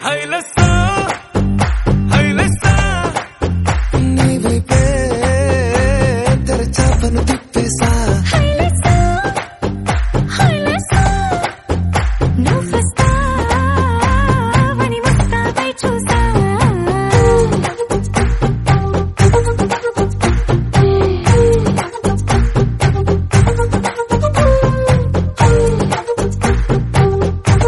CategoryTelugu Ringtones